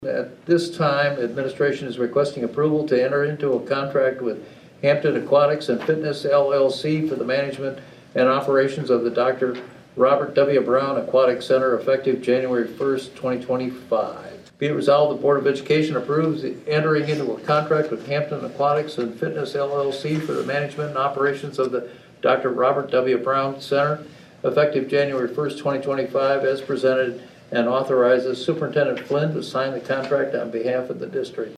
Board President Dr. Ed Lake read from the resolution.